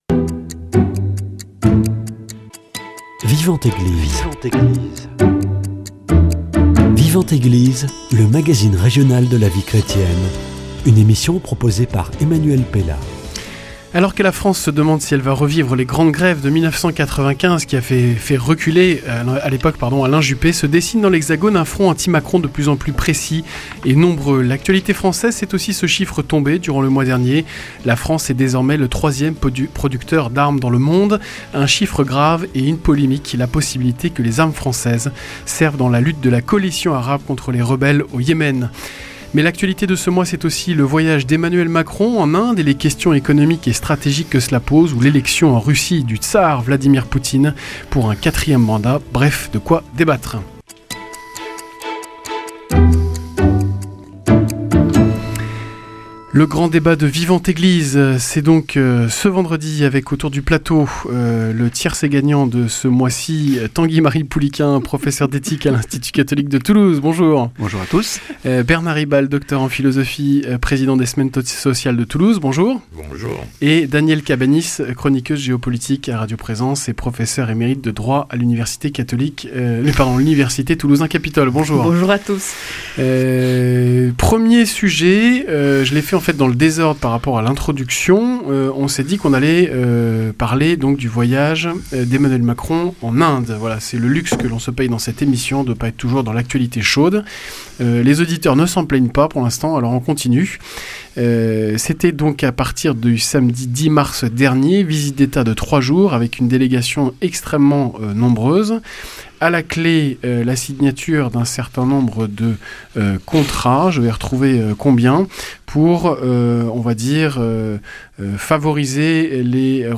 Le grand débat